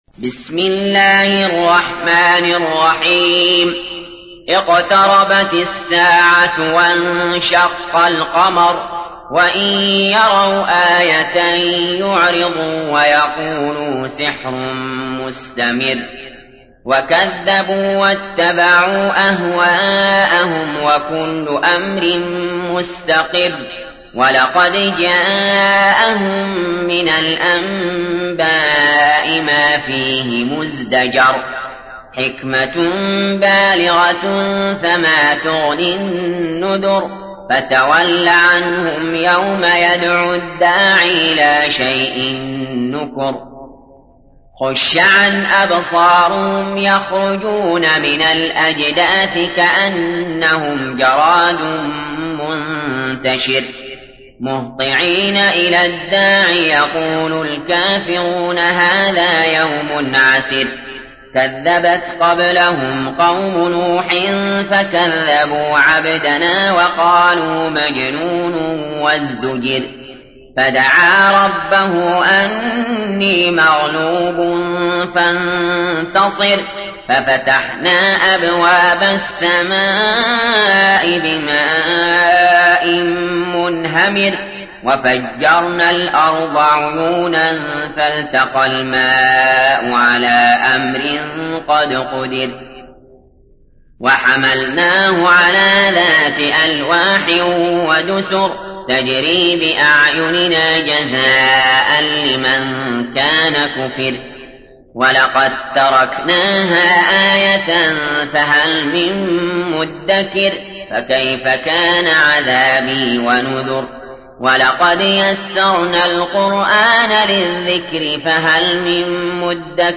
تحميل : 54. سورة القمر / القارئ علي جابر / القرآن الكريم / موقع يا حسين